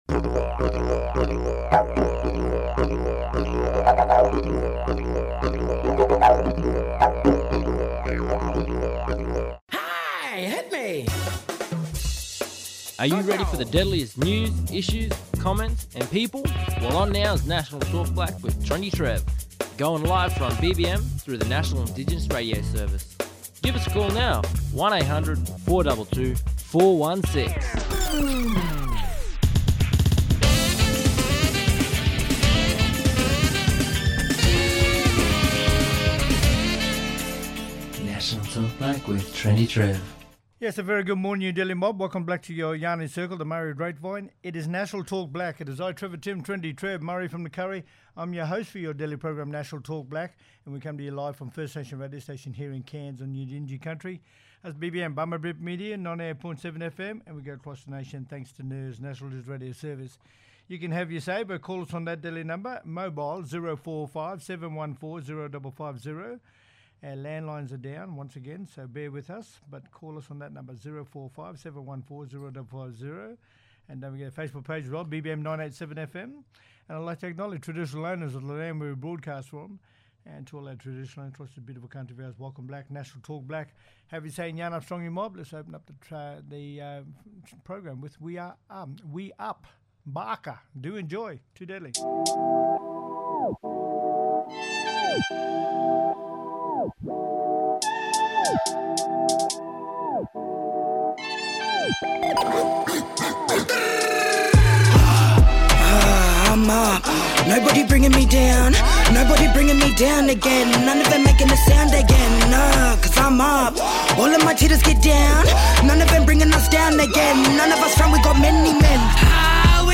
calling in from Coffs Harbour to have a deadly catch up yarn